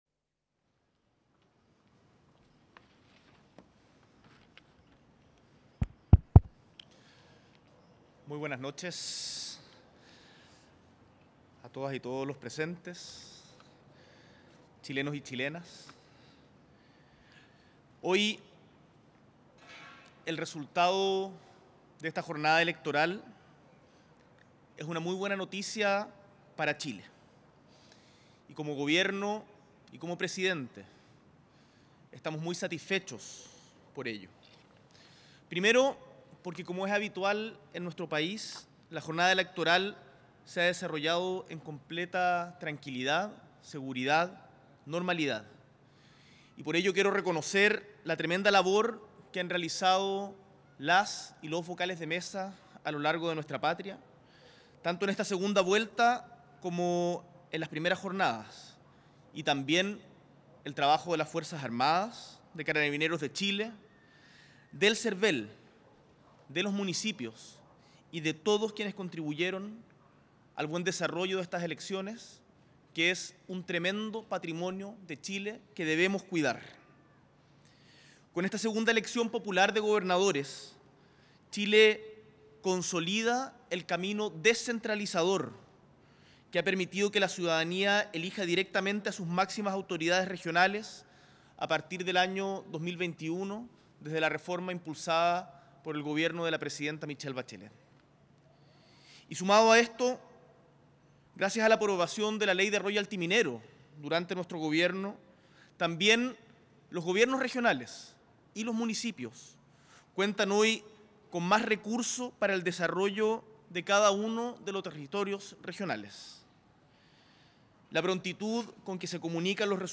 Presidente de la República, Gabriel Boric Font, realiza una declaración desde el Palacio de La Moneda tras la segunda vuelta electoral de gobernadores - Prensa Presidencia